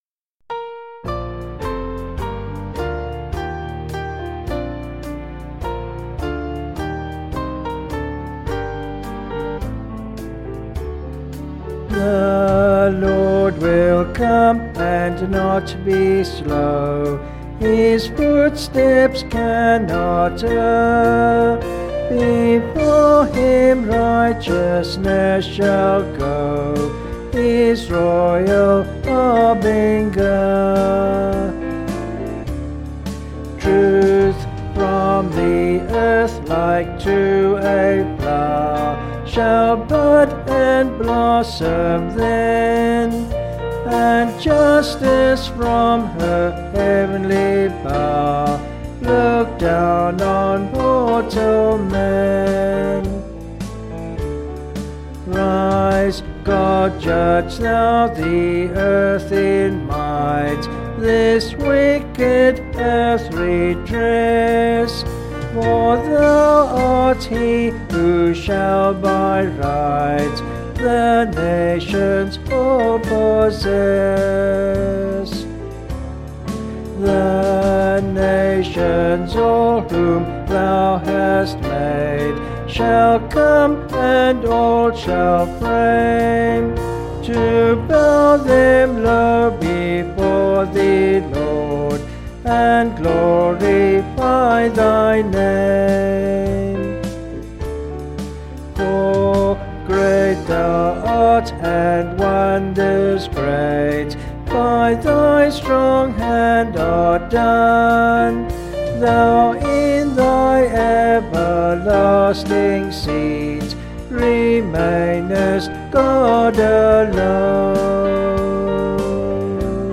Vocals and Band   264.1kb Sung Lyrics